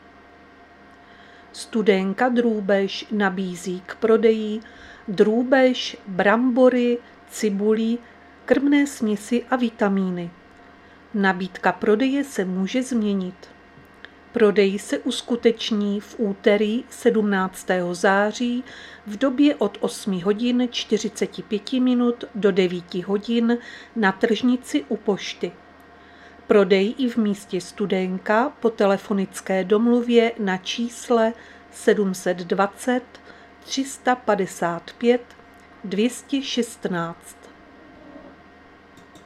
Záznam hlášení místního rozhlasu 16.9.2024